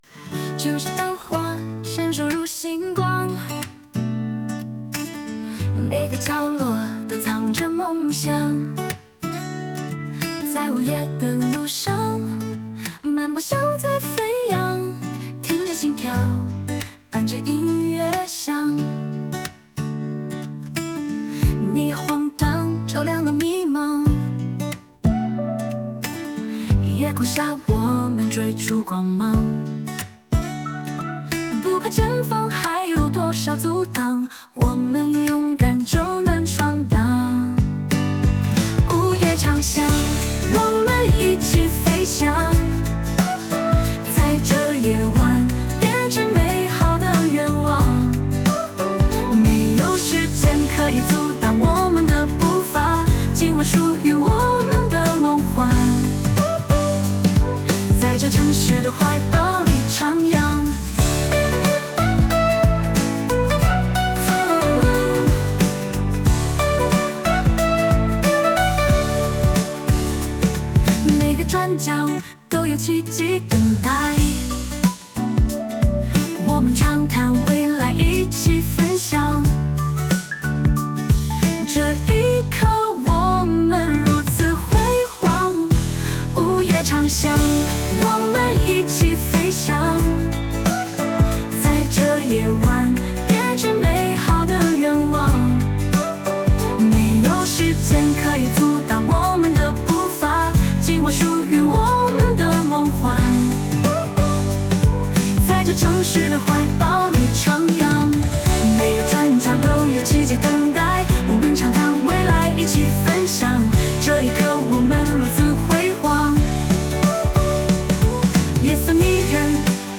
中国語の歌唱曲です。